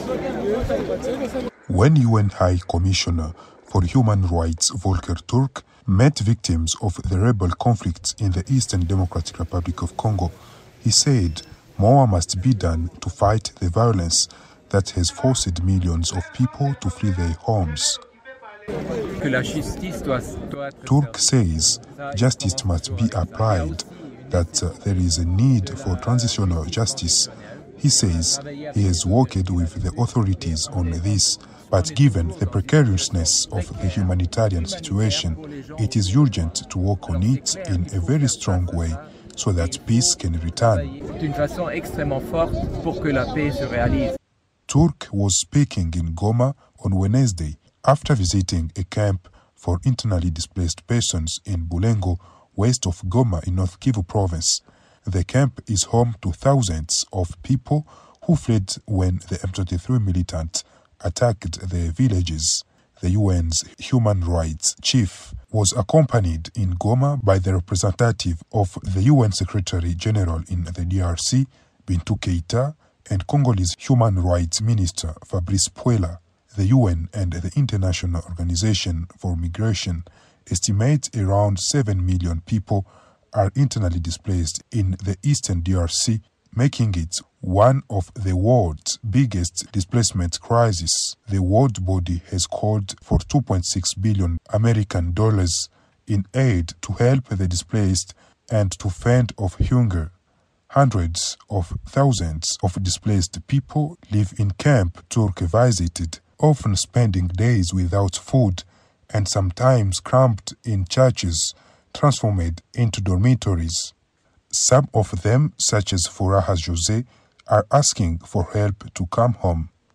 has details from Goma in the eastern DRC